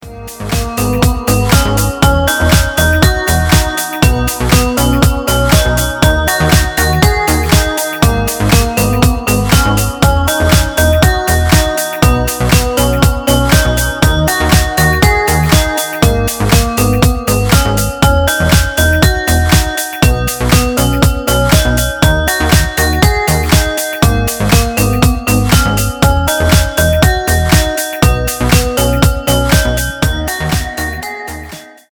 deep house , танцевальные
мелодичные
без слов